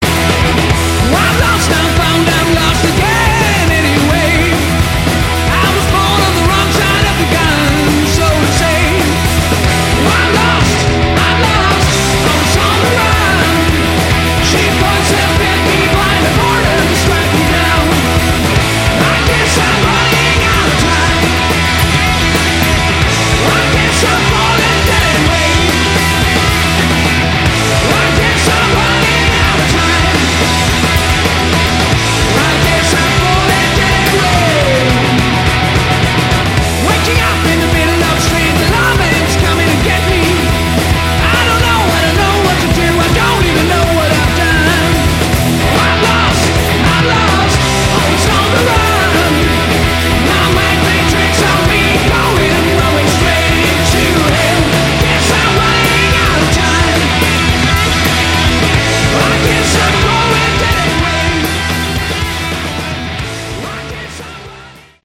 Category: Hard ROck
vocals, guitar
drums
bass